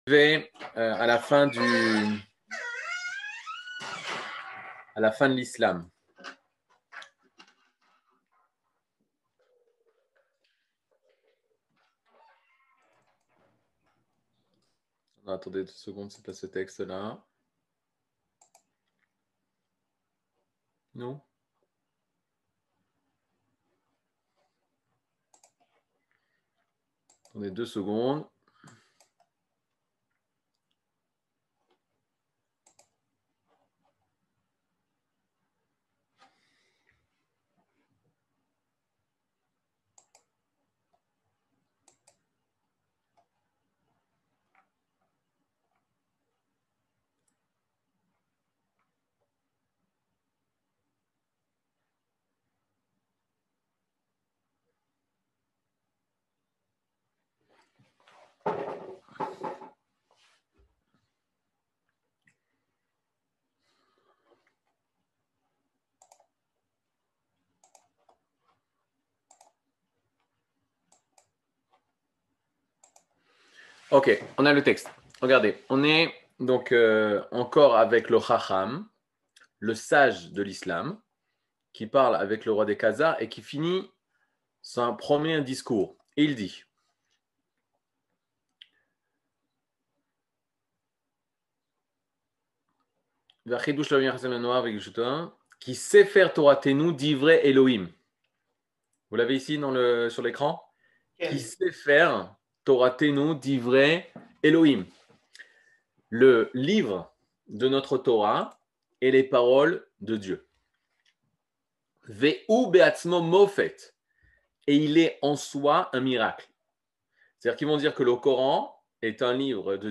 Catégorie Le livre du Kuzari partie 12 01:00:36 Le livre du Kuzari partie 12 cours du 16 mai 2022 01H 00MIN Télécharger AUDIO MP3 (55.47 Mo) Télécharger VIDEO MP4 (124.93 Mo) TAGS : Mini-cours Voir aussi ?